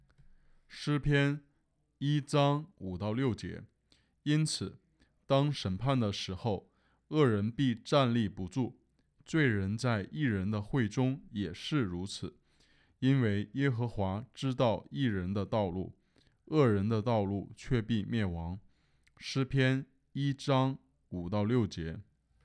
经文背诵